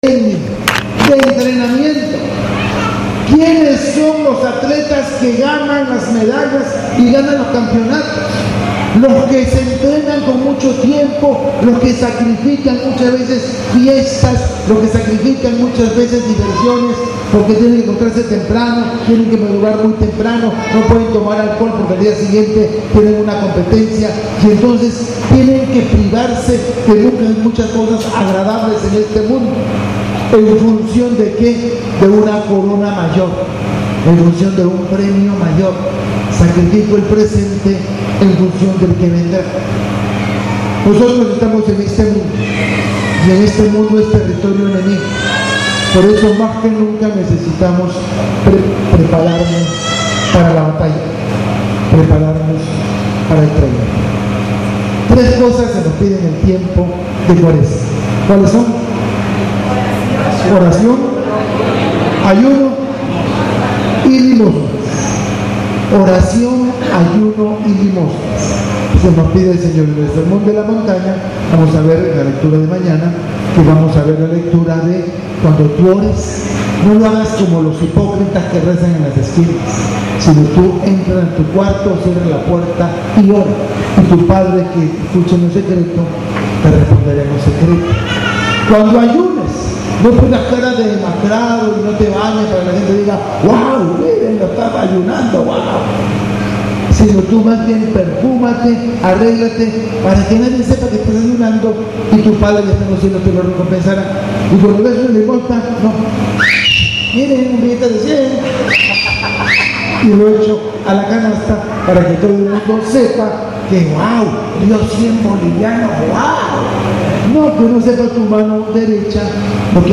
Homilia del martes 5/3/19